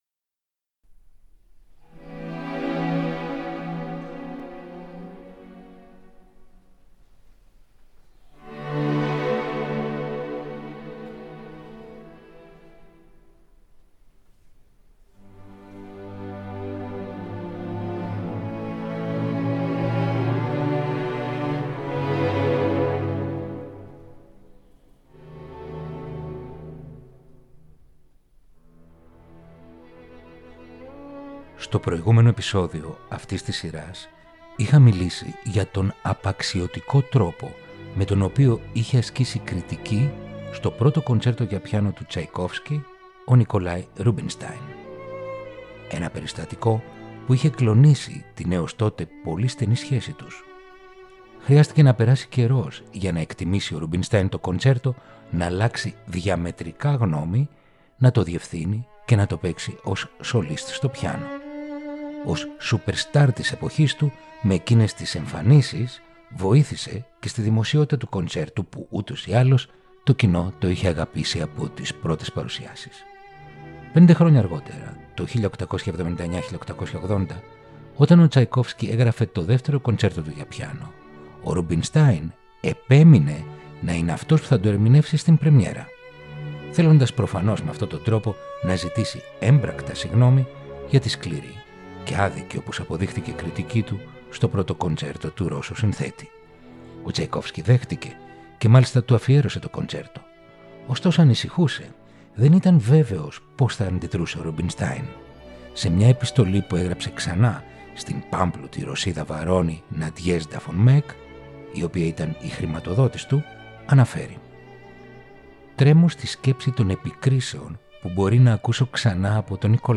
Ρομαντικά κοντσέρτα για πιάνο – Επεισόδιο 6ο